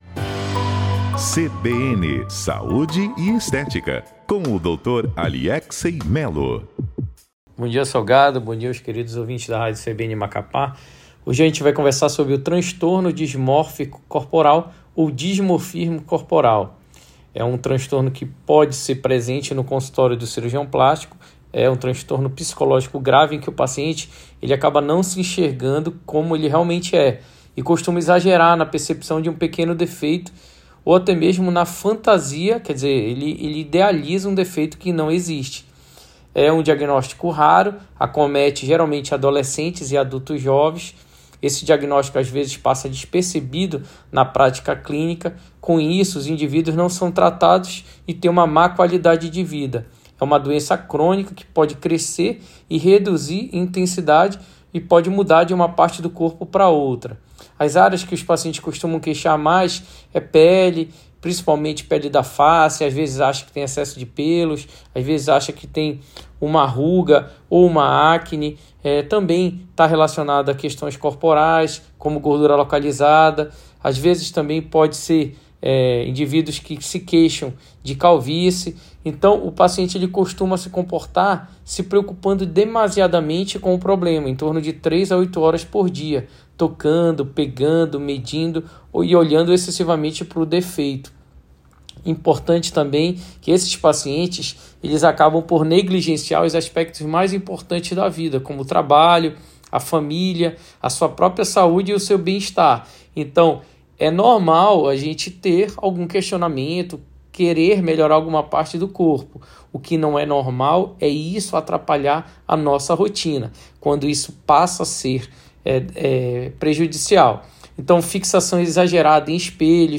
Cirurgião plástico fala sobre transtorno dismórfico corporal